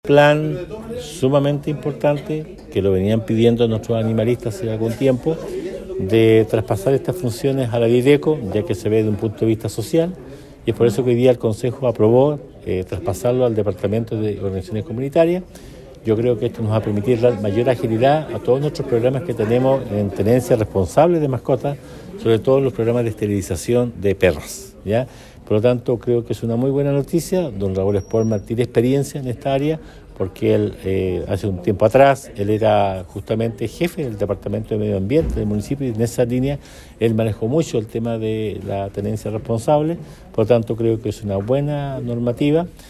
Así lo señaló el alcalde Emeterio Carrillo, quien enfatizó en que la tenencia responsable necesita un enfoque social, con personal que mantiene experiencia en el tema.